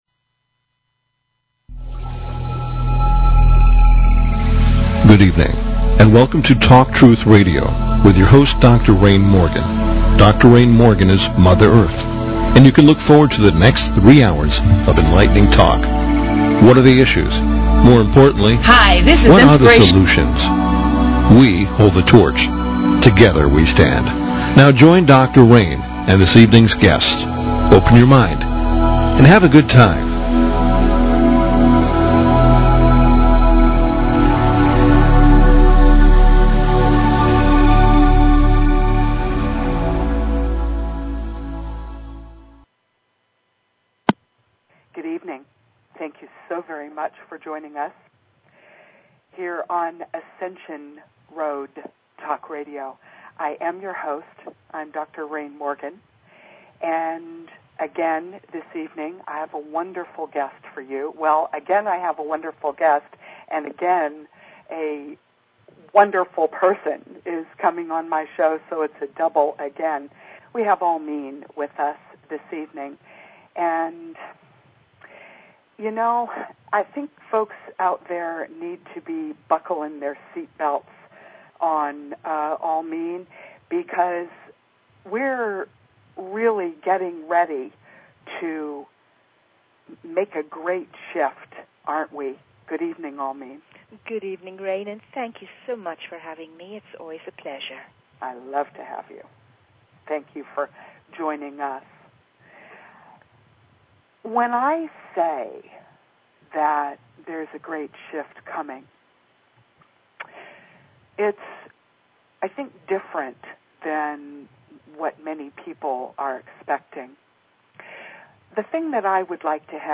Talk Show Episode, Audio Podcast, Ascension_Road and Courtesy of BBS Radio on , show guests , about , categorized as